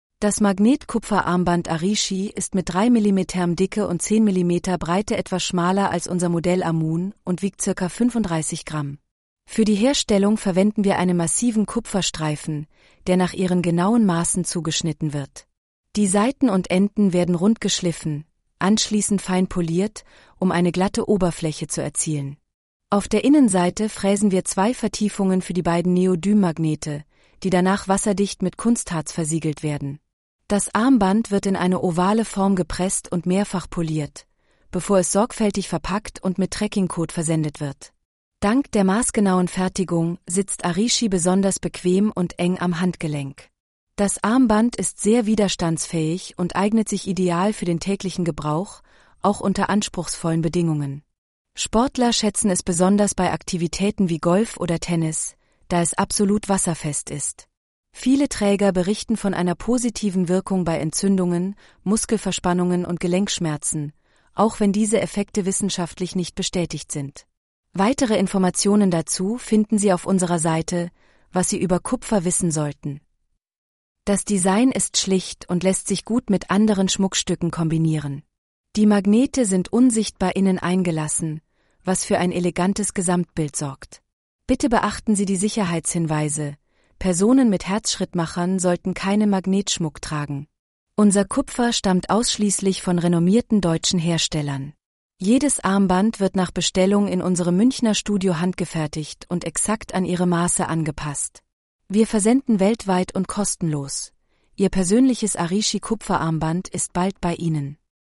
Arishi-rttsreader.mp3